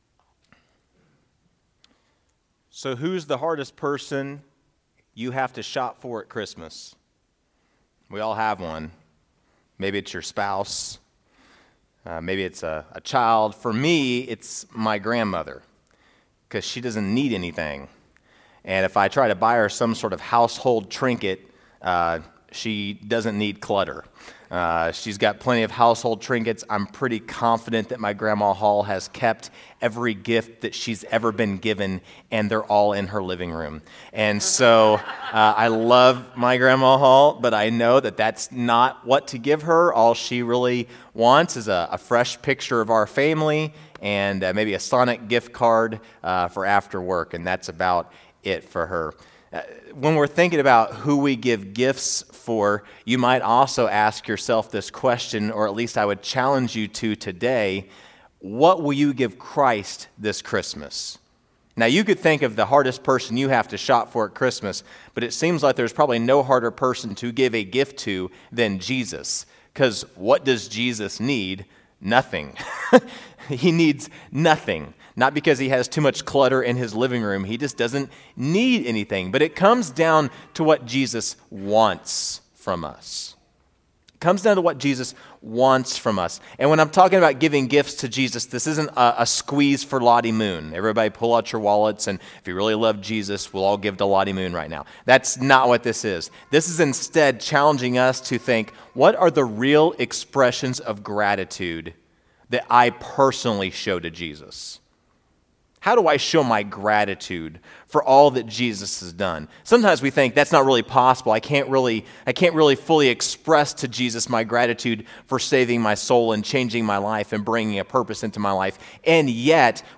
First Baptist Church of Machesney Park Sermon Audio